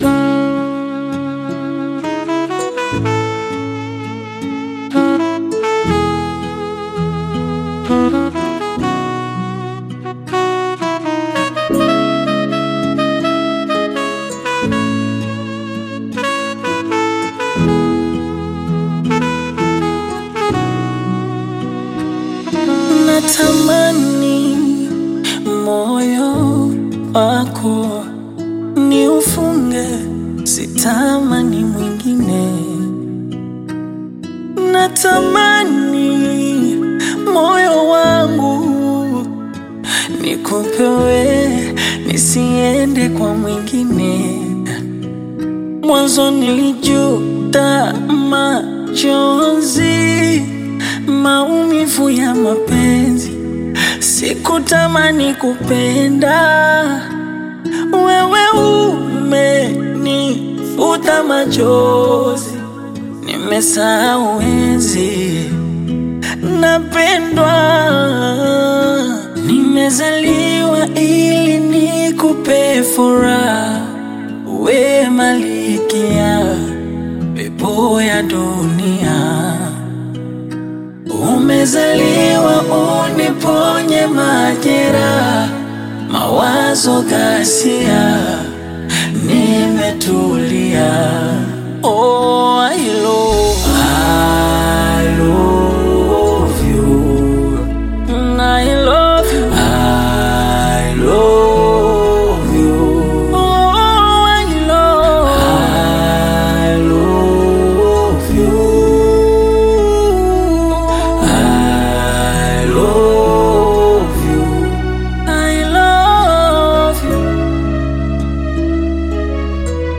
Tanzanian Bongo Fleva artist